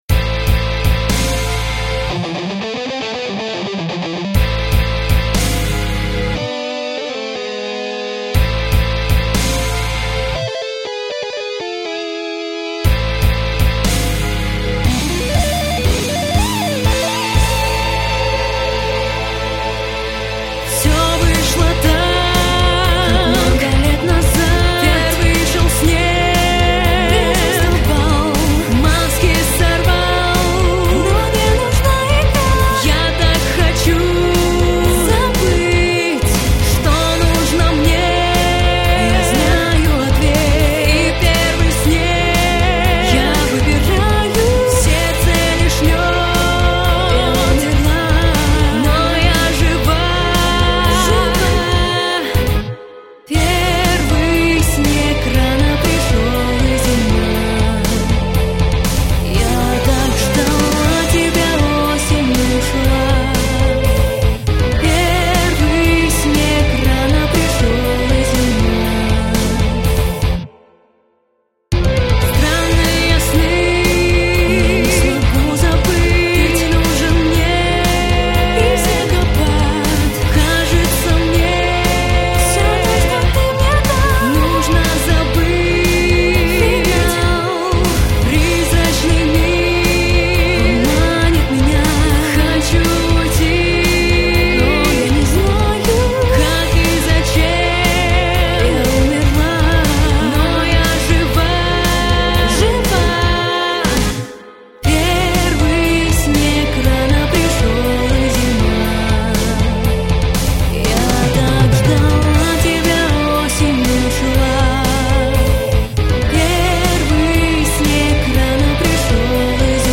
• Жанр: Металл